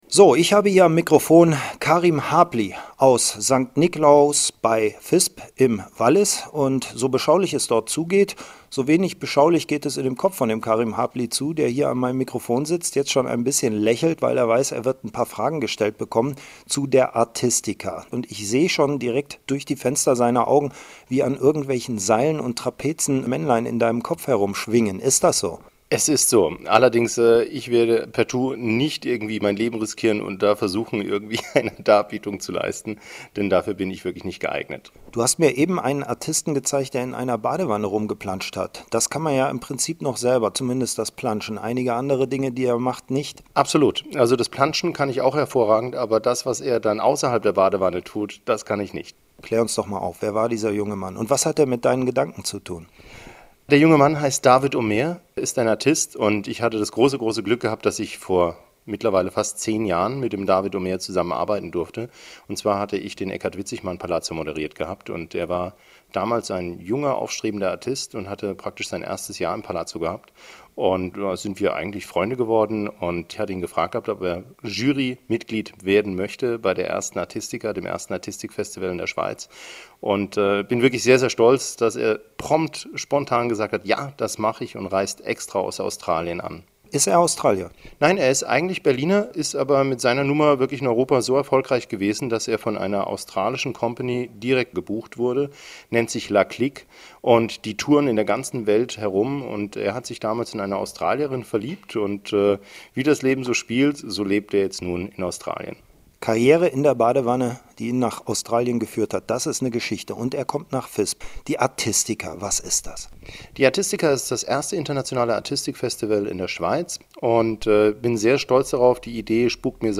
kurzinterview.mp3